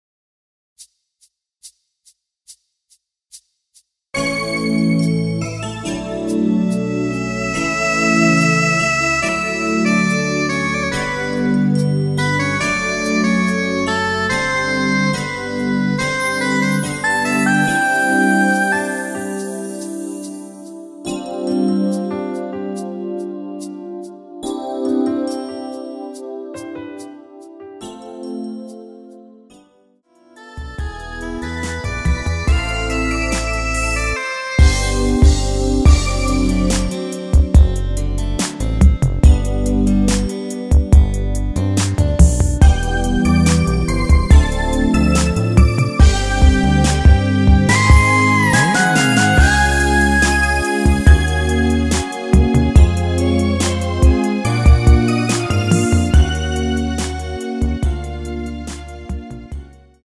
축가를 짧게 해야될때 이용 하시면 좋은 MR
◈ 곡명 옆 (-1)은 반음 내림, (+1)은 반음 올림 입니다.
앞부분30초, 뒷부분30초씩 편집해서 올려 드리고 있습니다.
중간에 음이 끈어지고 다시 나오는 이유는